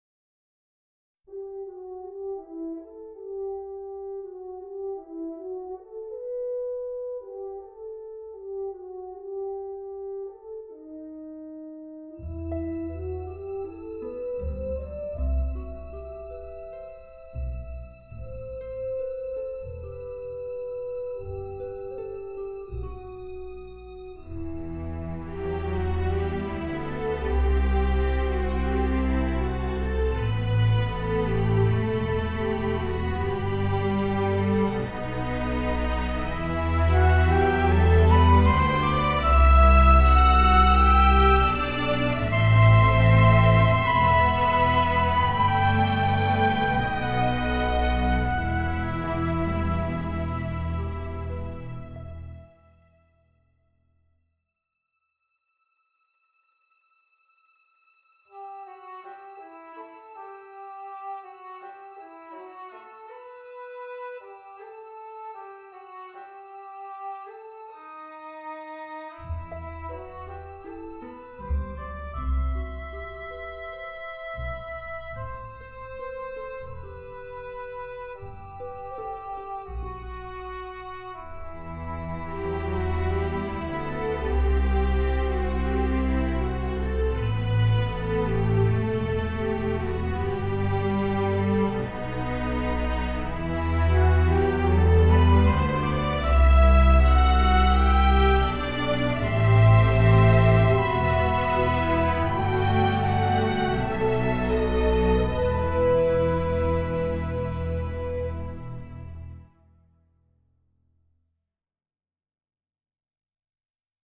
I mocked up a demo in Logic Pro using virtual instruments.